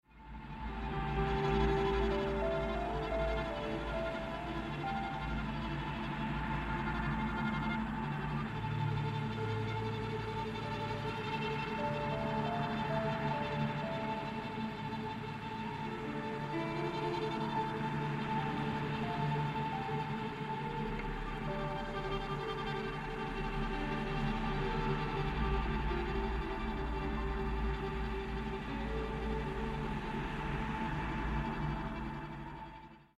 a broad spectrum of musical sounds